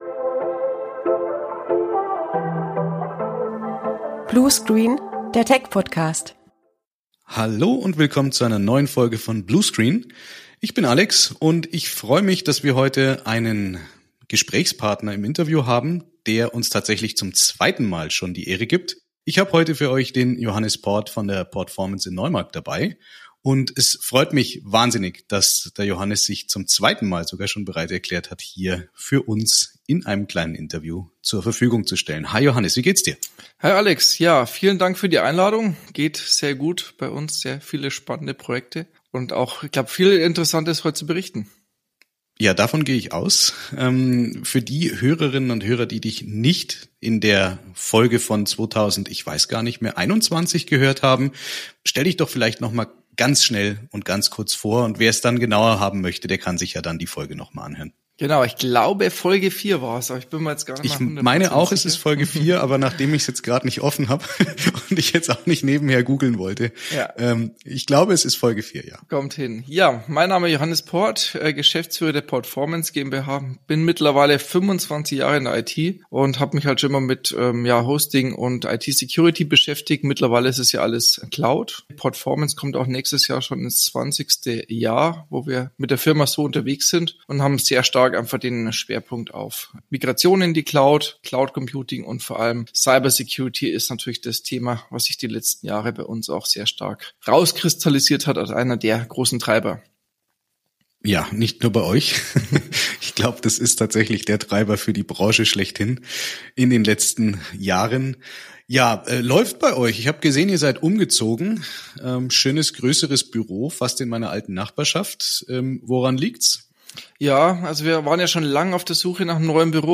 Folge 40 von Bluescreen - Der Tech-Podcast! Im Interview